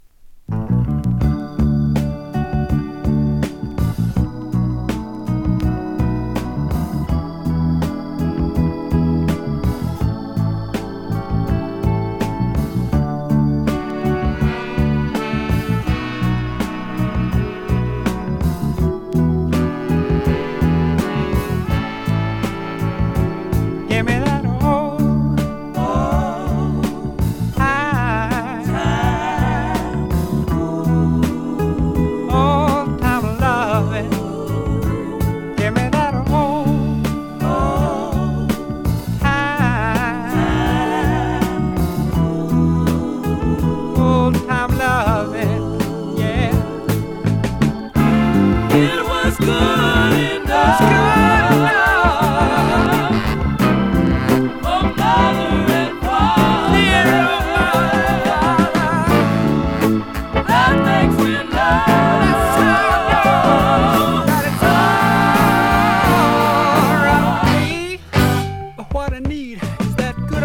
マッスル・ショールズ録音。